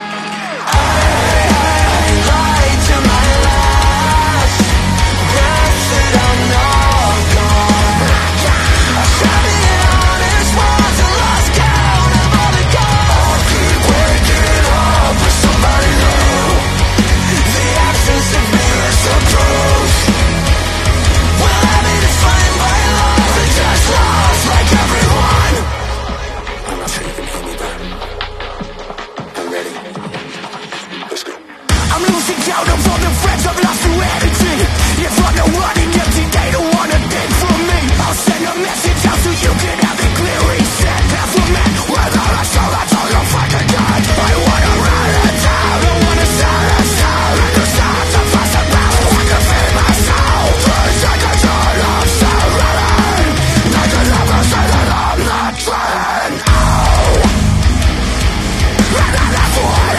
its powerful \ emotional \ beautiful